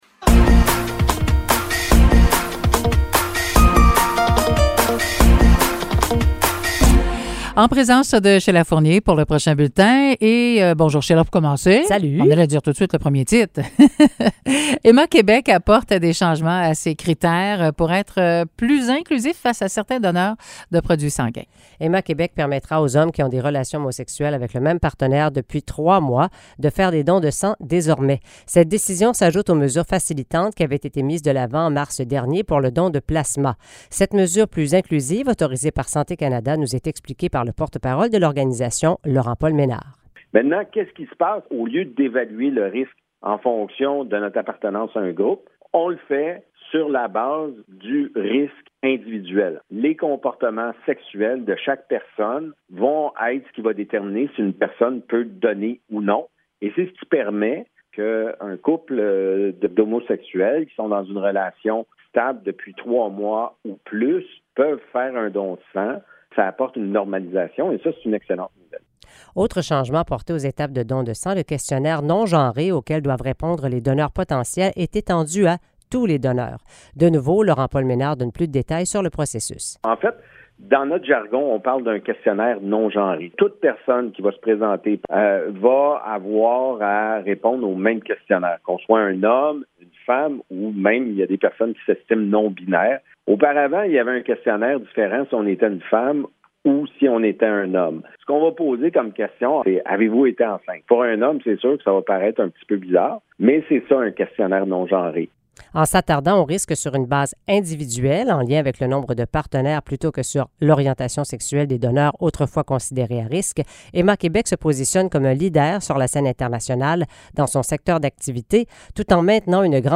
Nouvelles locales - 7 décembre 2022 - 10 h